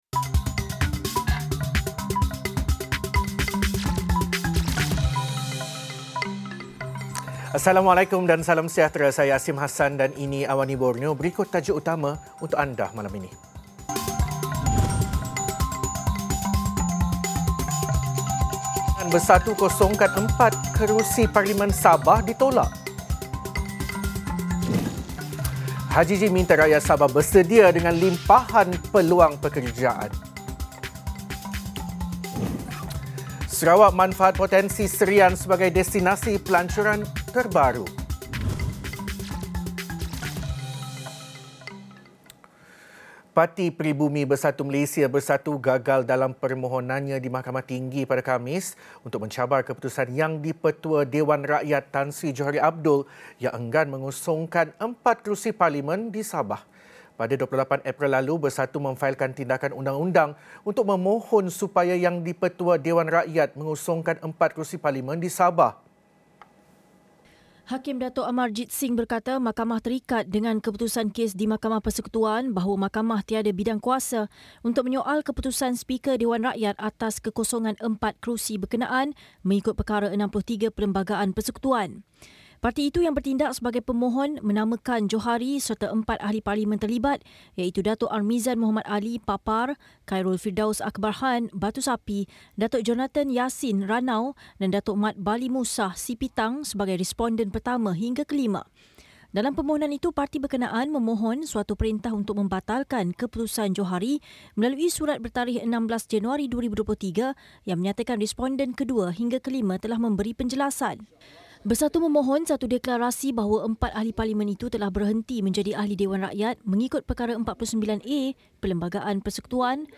Laporan berita padat dan ringkas dari Borneo